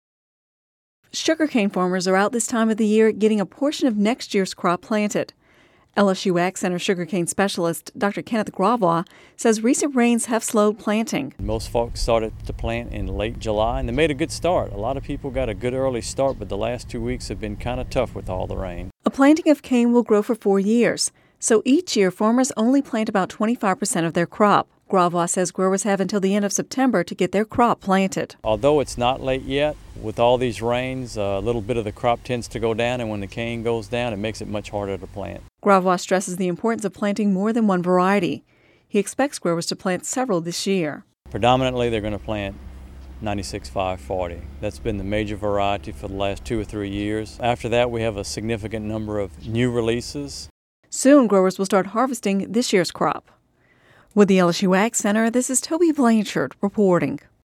(Radio News 08/30/10) Sugarcane farmers are out this time of the year, getting a portion of next year’s crop planted.